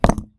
brick-castle.ogg